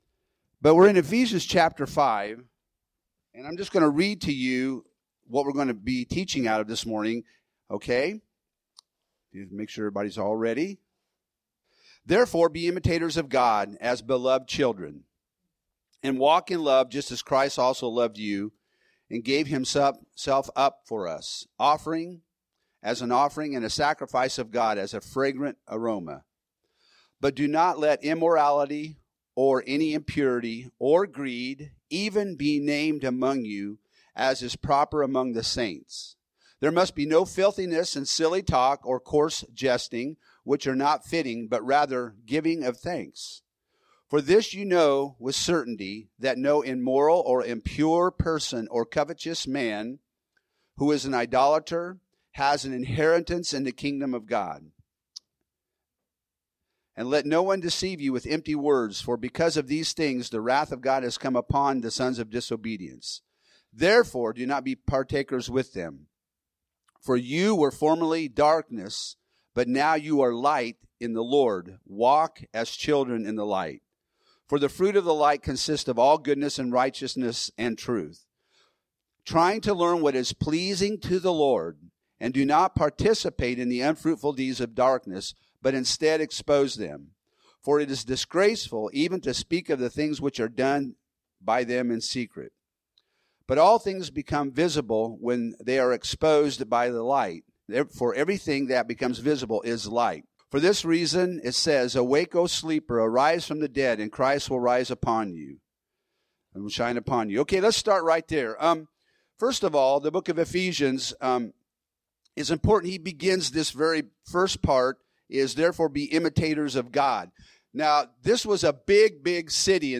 Home › Sermons › Ephesians 5:1~8